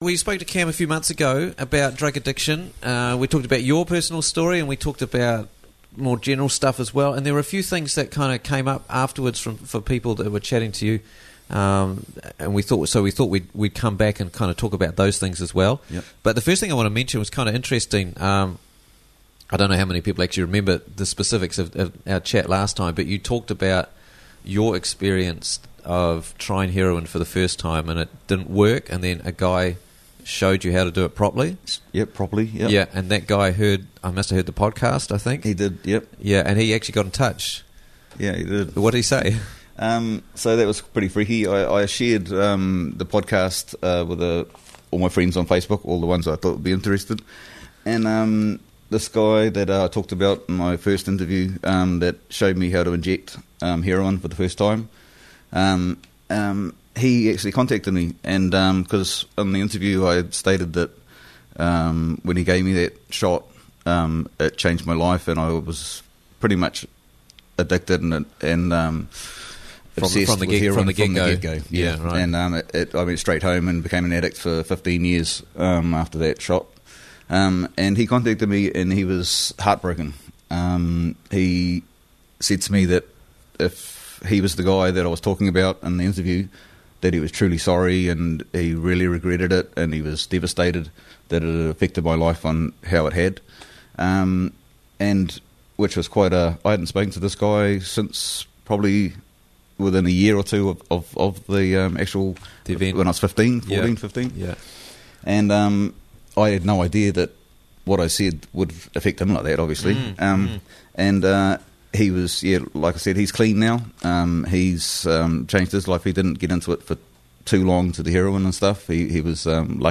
Escaping Opiate Addiction - Interviews from the Raglan Morning Show